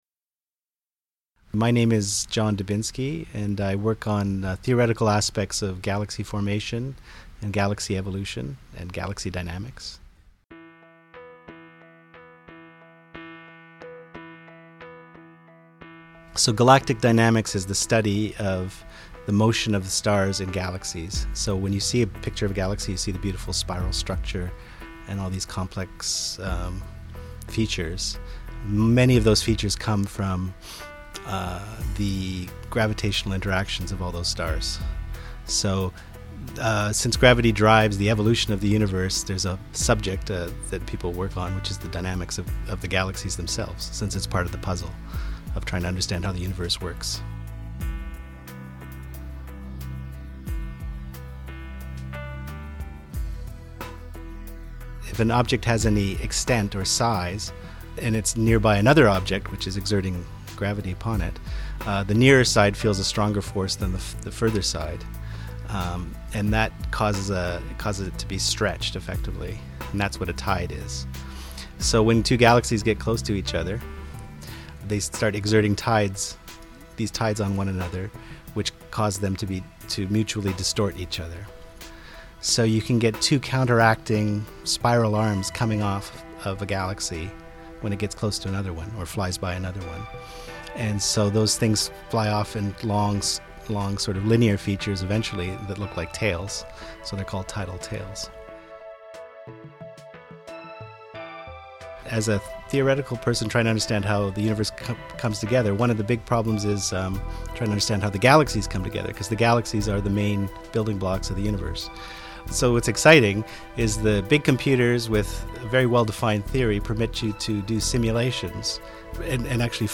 Listen to one Toronto astronomer talk about his interest in neutrinos and exploding stars.